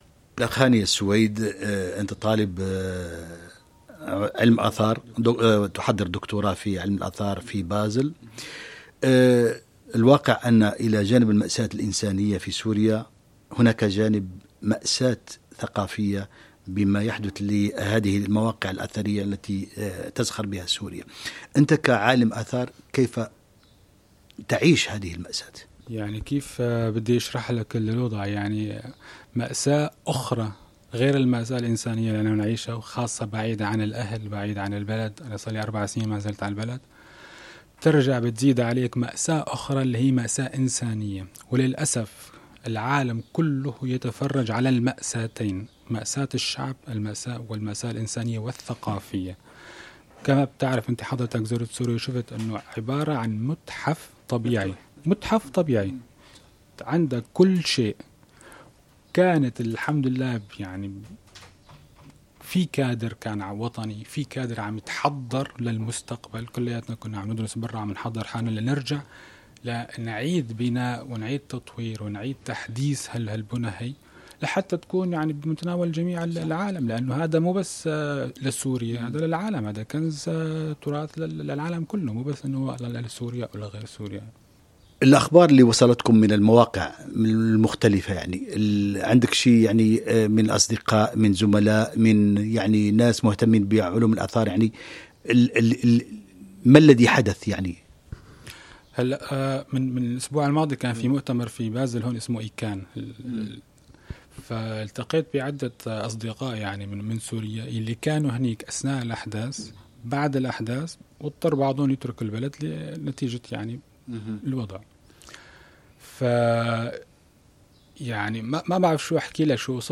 في لقاء خاص